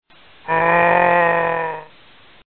mgroan2.mp3